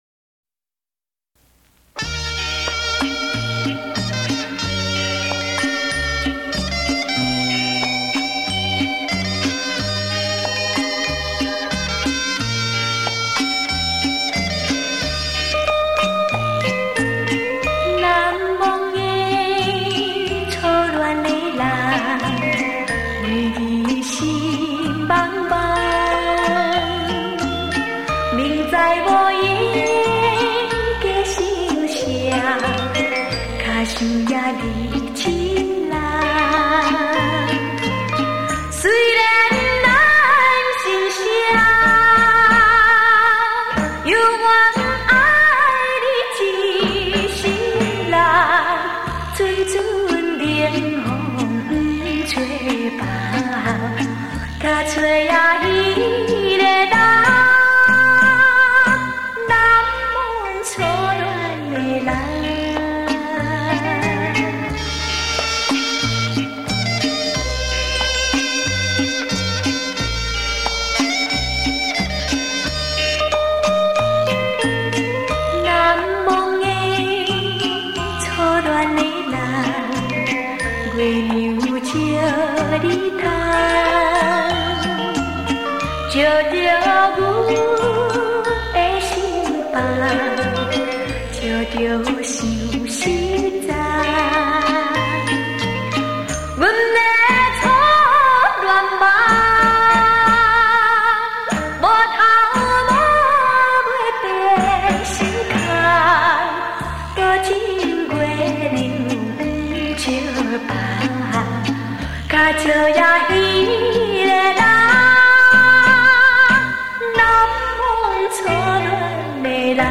脍炙人口怀念金曲
温馨甜蜜耐人寻味